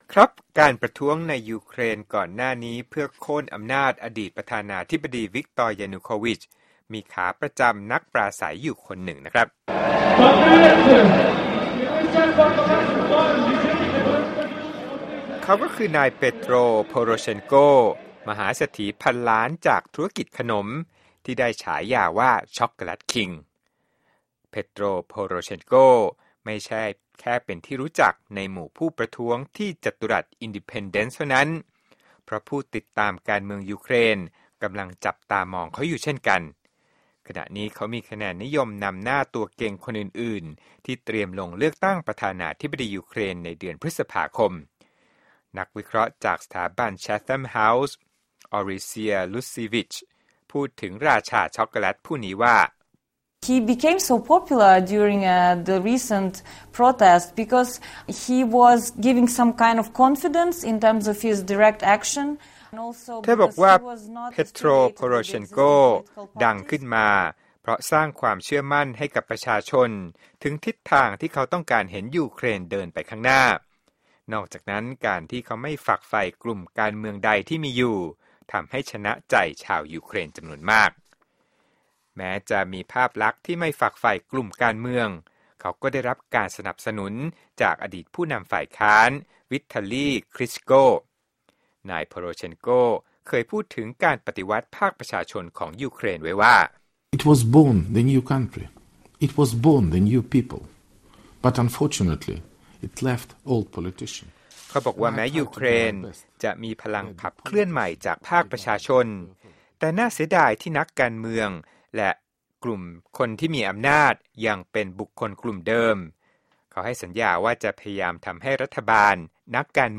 โปรดติดตามรายละเอียดจากคลิปเรื่องนี้ในรายการข่าวสดสายตรงจากวีโอเอ